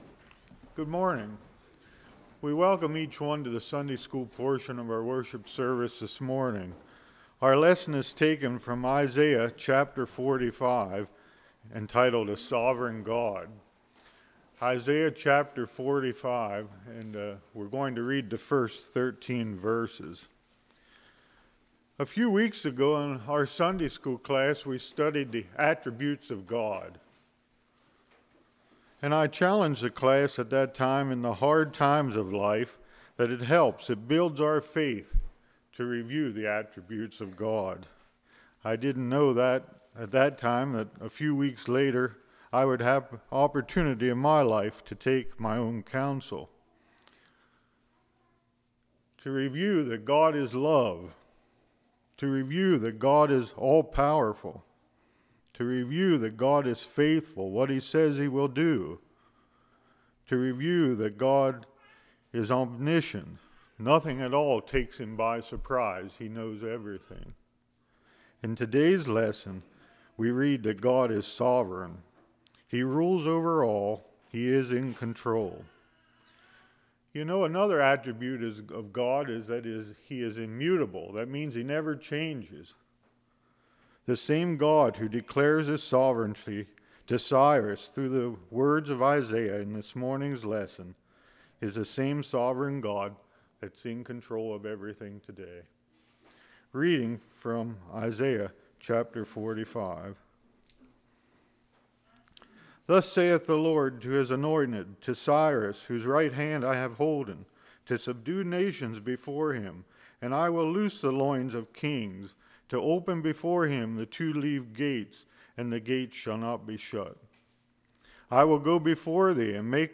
Passage: Isaiah 45:1-13 Service Type: Sunday School